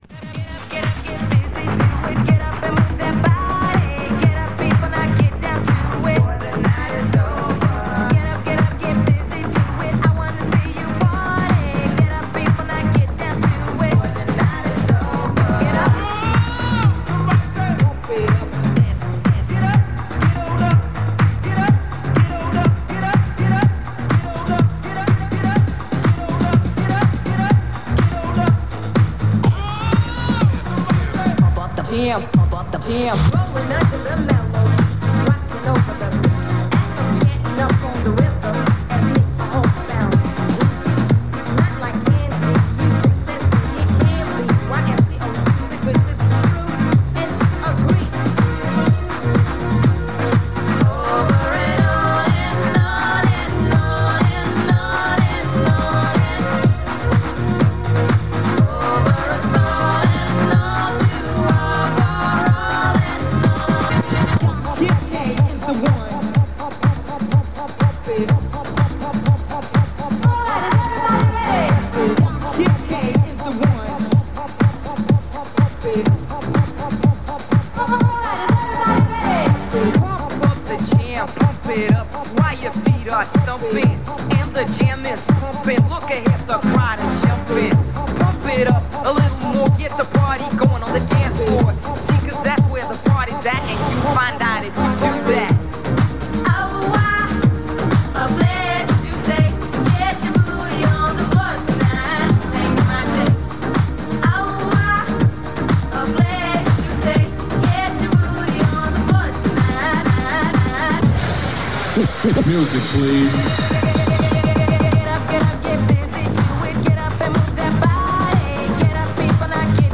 continuous dance music interspersed with jingles
After a two-hour loop of music and the recorded announcement, WABC closed down shortly before 1915.